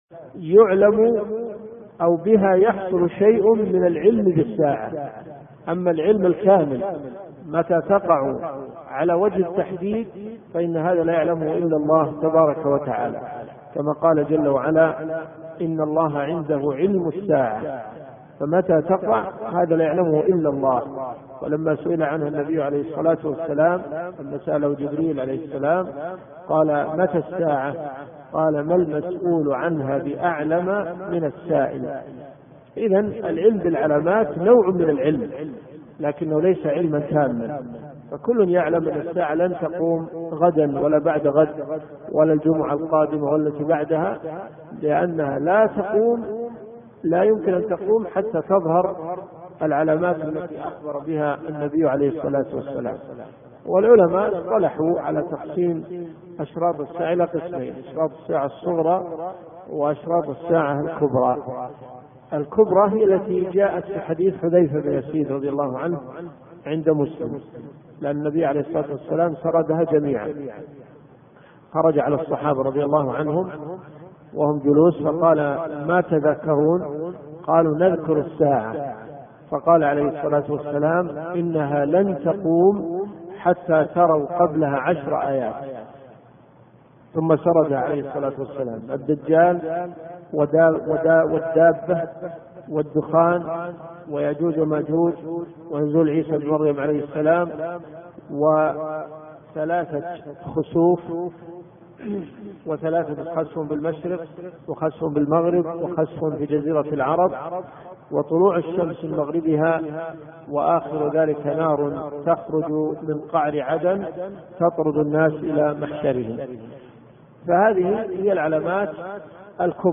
الرئيسية الدورات الشرعية [ قسم العقيدة ] > لمعة الاعتقاد . 1426 .